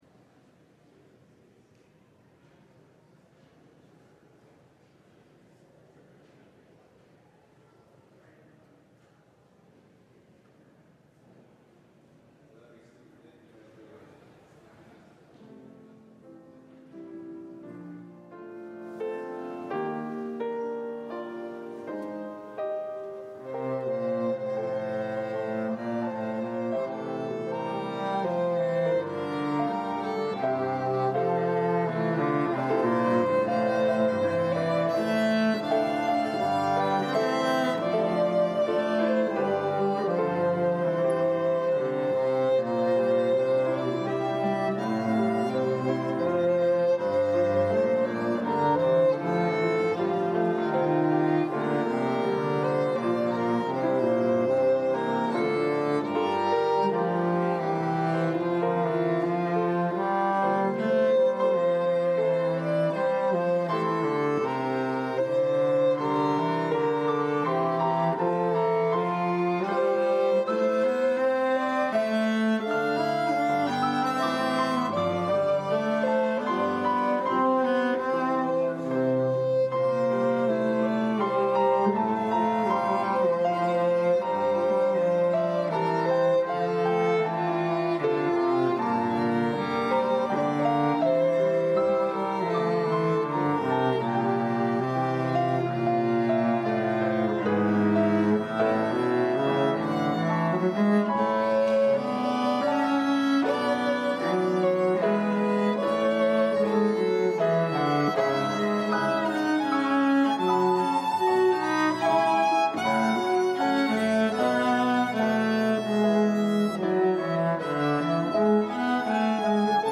LIVE Midday Worship Service - The Imperfect Anointed: Last Words: The Death of David